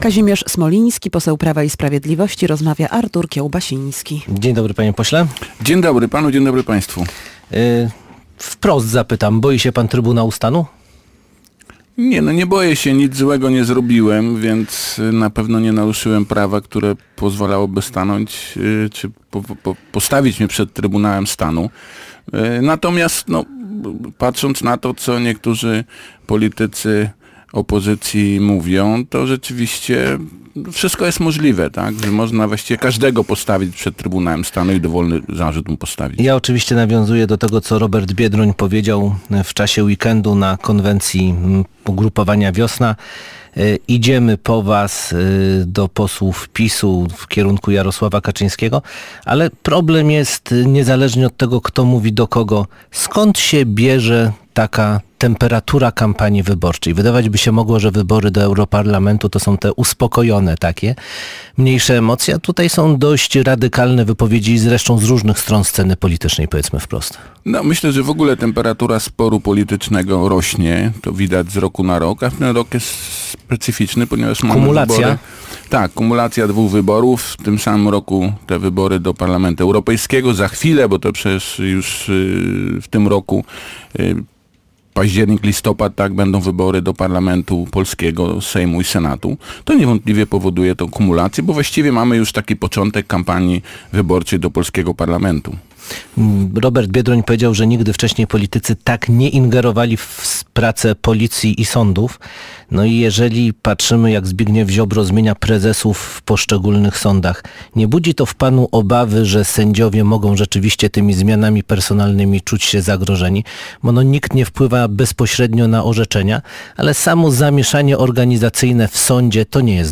Gościem Dnia Radia Gdańsk był Kazimierz Smoliński, poseł Prawa i Sprawiedliwości.